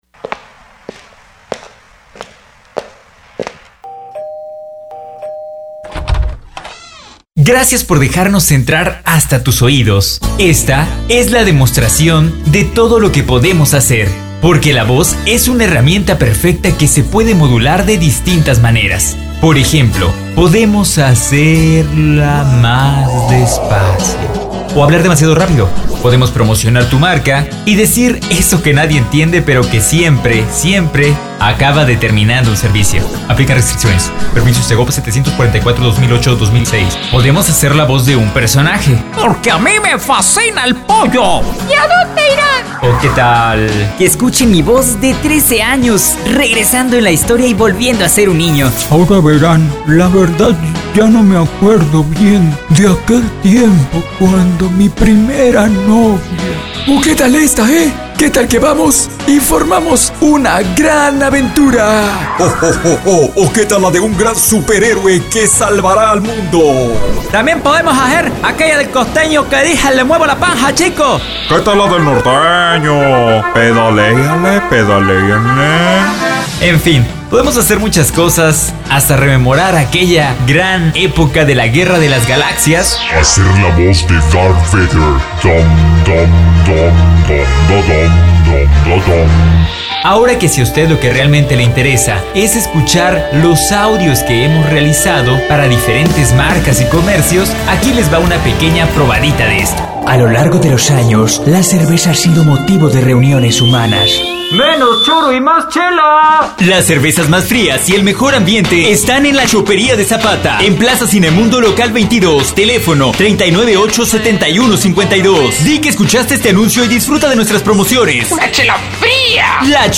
Producción de spots para perifoneo.
optima DEMO VOZ Y CREATIVIDAD POST.mp3